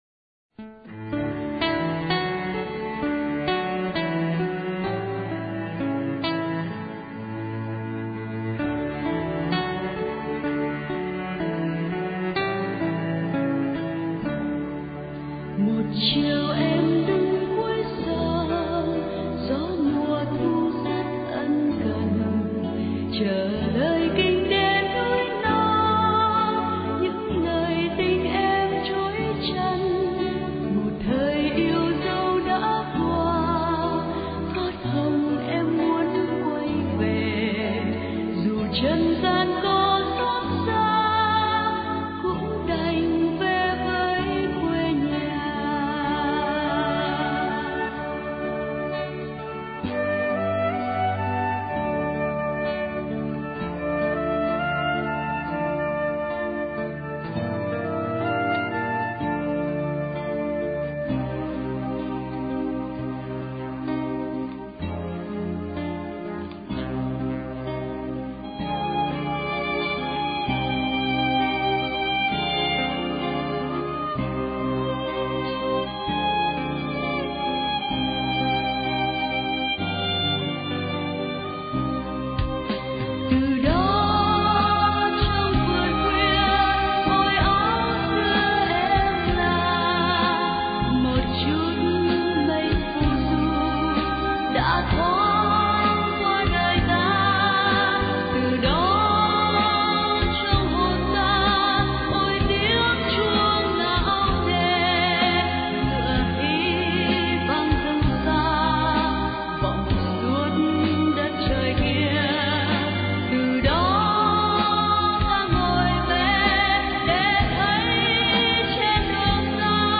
Nhạc nền :